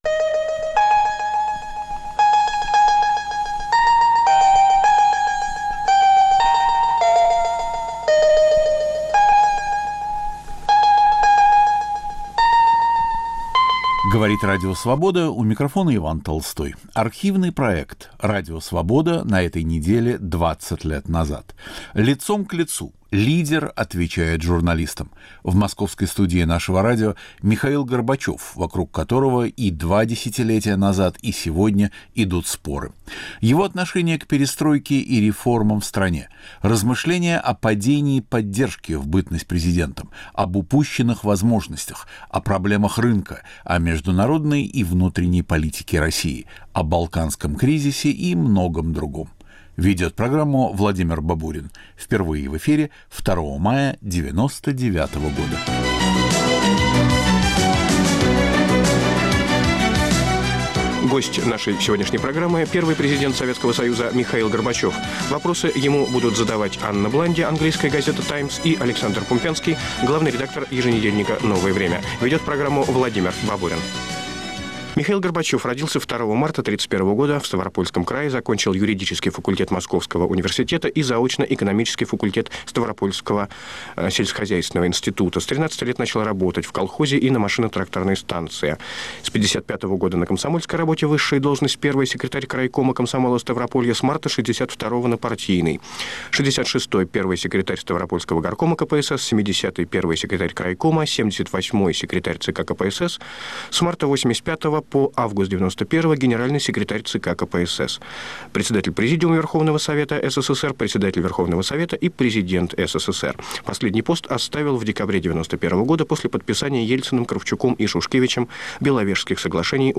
Радио Свобода на этой неделе 20 лет назад. Михаил Горбачев в студии "Свободы"
Архивный проект.